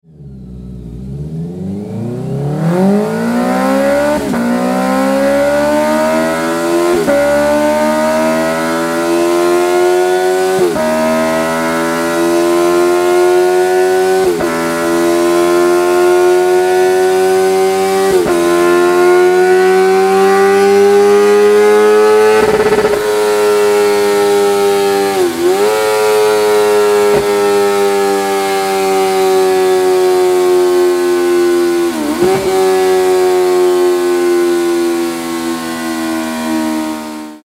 MIVV Komplettanlage GP-Pro Edelstahl schwarz HONDA CBR 650 R 24-26